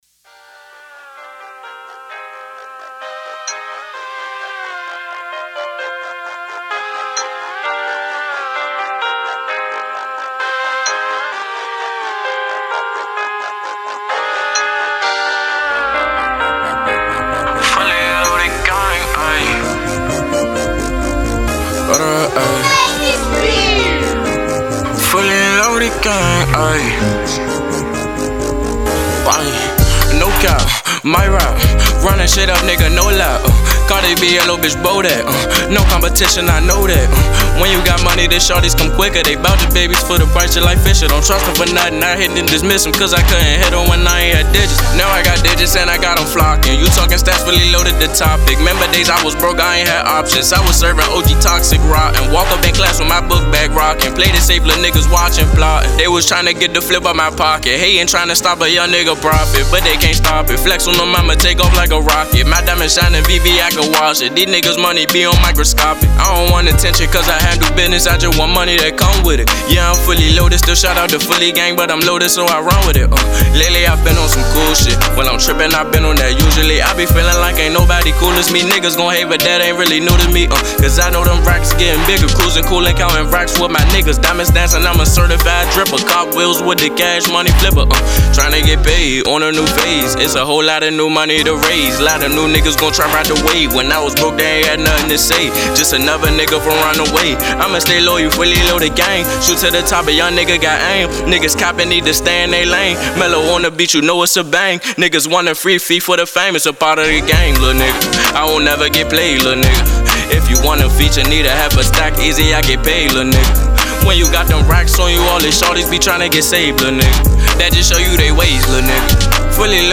An Indie Hip Hop Artist from Washington, DC.